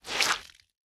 Minecraft Version Minecraft Version 1.21.5 Latest Release | Latest Snapshot 1.21.5 / assets / minecraft / sounds / block / sponge / absorb3.ogg Compare With Compare With Latest Release | Latest Snapshot
absorb3.ogg